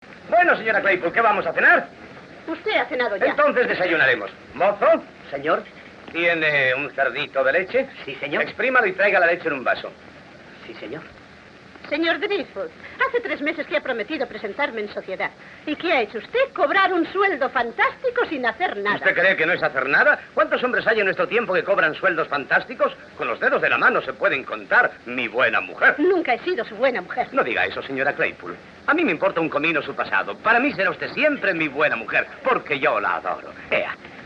SONIDO (VERSIÓN DOBLADA)
La versión doblada al castellano comparte con la pista original su limitado rango dinámico y su considerable y molesto ruido de fondo.
El volumen del sonido en castellano es algo más alto que el del inglés, pero el nivel de distorsión es similar o incluso inferior.
El único elogio que podemos hacer de este sonido es que nos permite seguir correctamente las voces y que la música y los efectos no suenan peor (aunque tampoco mejor) que en la pista original inglesa.